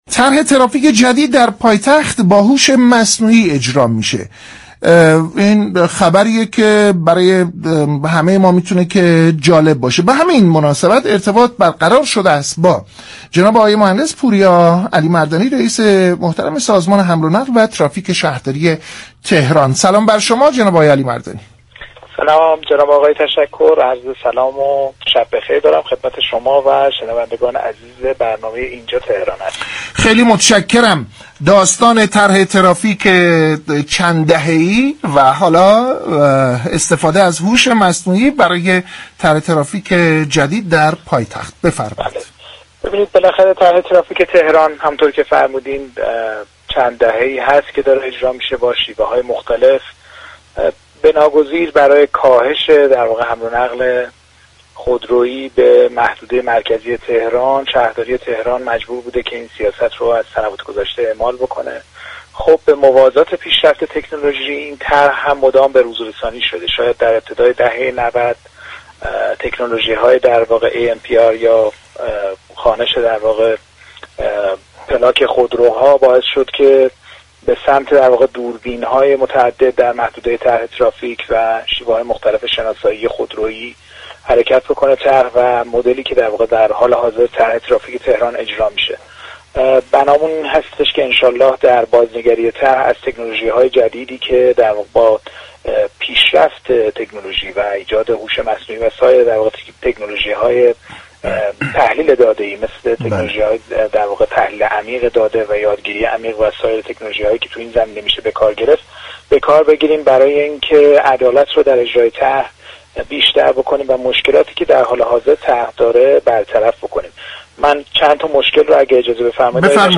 طرح ترافیك جدید پایتخت با هوش مصنوعی اجرا می‌شود به گزارش پایگاه اطلاع رسانی رادیو تهران؛ پوریا علیمردانی رئیس سازمان حمل و نقل و ترافیك شهرداری تهران در گفتگو با برنامه «اینجا تهران است» با بیان اینكه شهرداری چند دهه است كه طرح ترافیك تهران را با شیوه‌های مختلف به منظور كاهش حمل و نقل خودرویی در محدوده مركزی شهر اعمال كرده است گفت: طی این سالها، طرح ترافیك به موازات پیشرفت تكنولوژی با امكانات جدید از جمله دوربین‌های پلاك‌خوان بروزرسانی شده است.